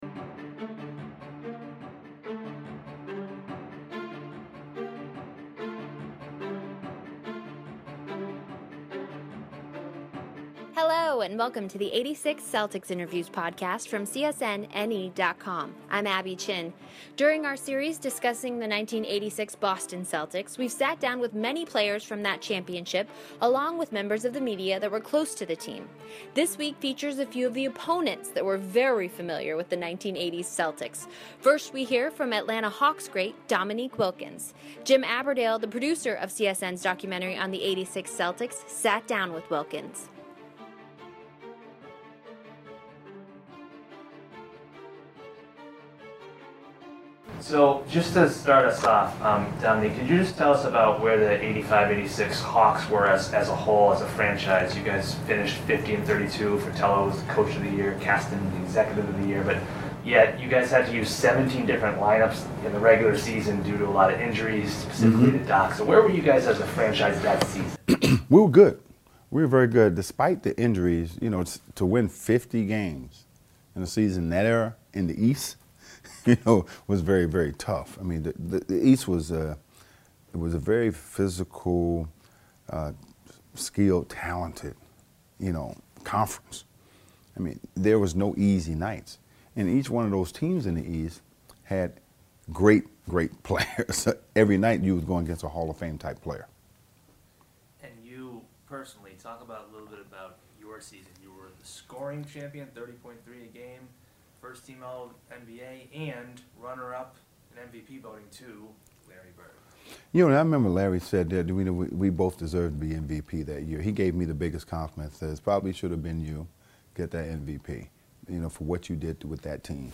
This week features a few of the opponents that were very familiar with the 1980’s Celtics - Atlanta Hawks legend Dominique Wilkins, former Celtics coach (and Hawk) Doc Rivers, and Lakers great James Worthy.